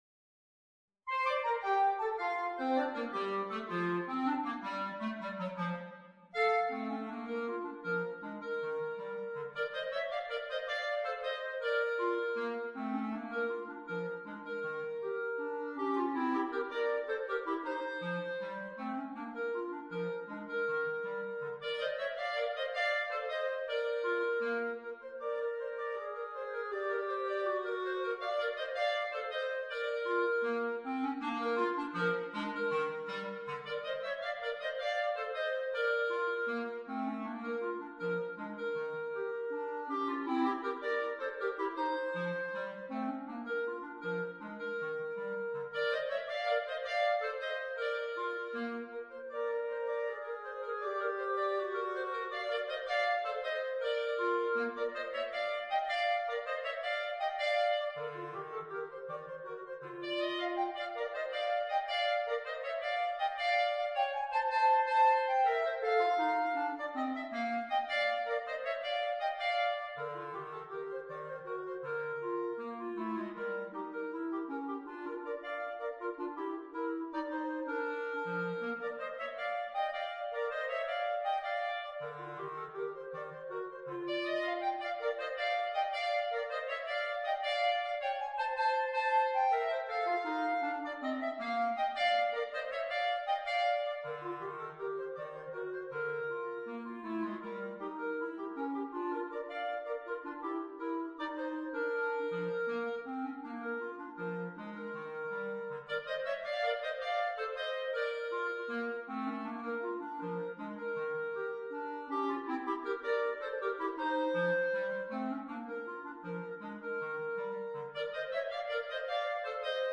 per due clarinetti